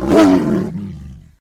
combat / creatures / tiger / he / attack3.ogg